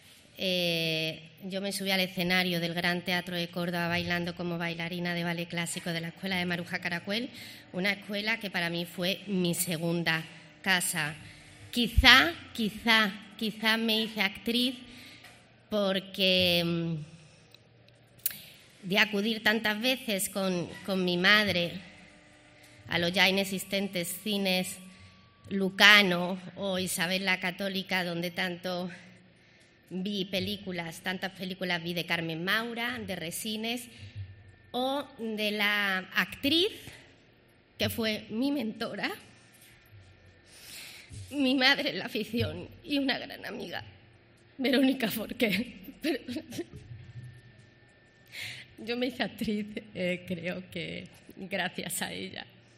Escucha a Macarena Gómez al recoger a Bandera de Andalucía en Córdoba